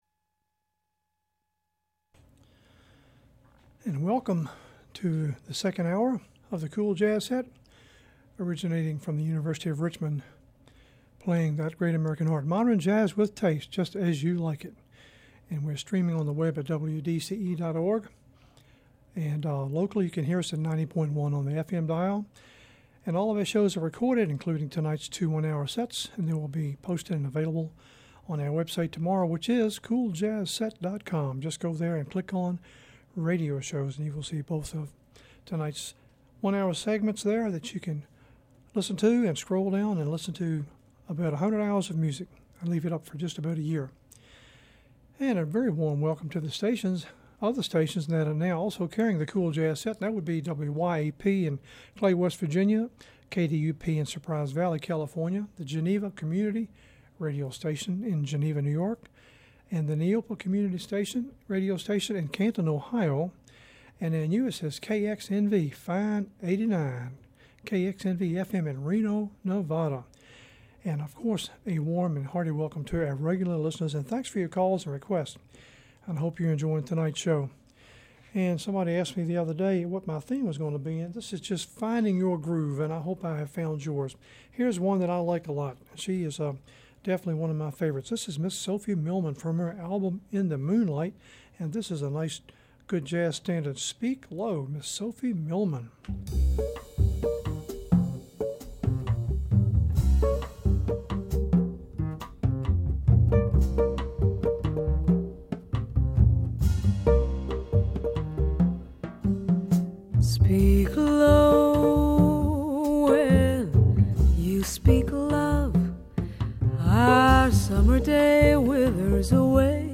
Finding Your Groove; 8/16/15; Set #2 Subtitle: cool jazz set Program Type: Unspecified Speakers: Version: 1 Version Description: Version Length: 1:02 a.m. Date Recorded: Aug. 16, 2015 1: 1:02 a.m. - 58MB download